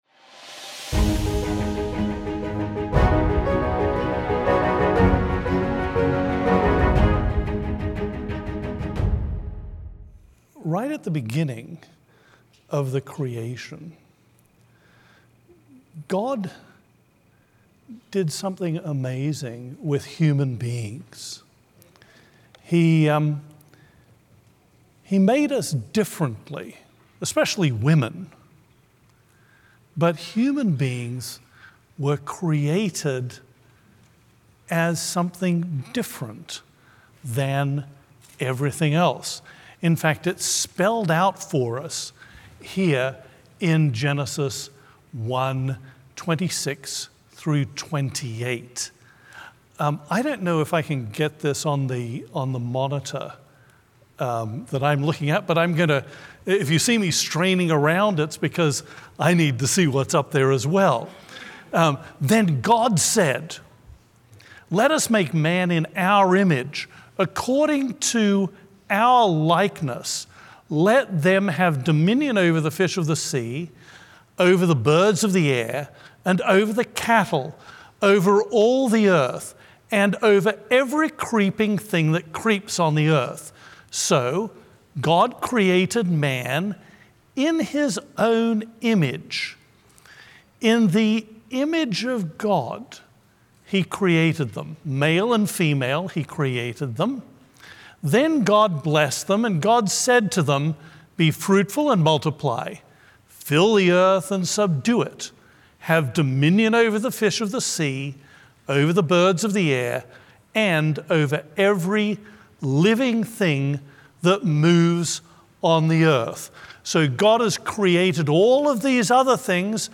A message from the series "Central Sermons."